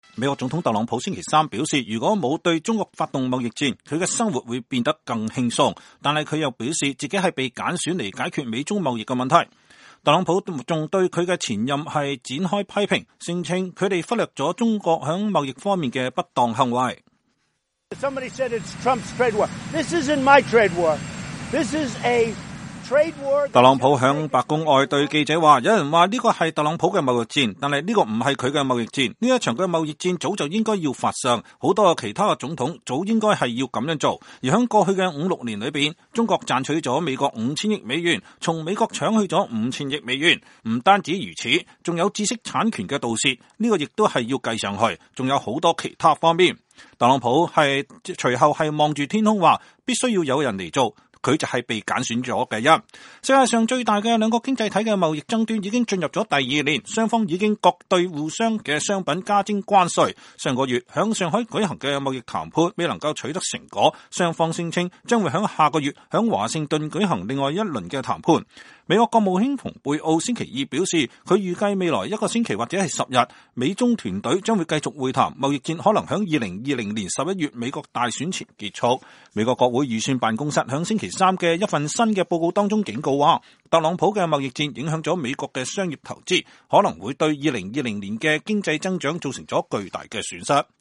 特朗普總統在登上“海軍陸戰隊一號”直升機前在白宮南草坪對媒體講話。 (2019年8月21日)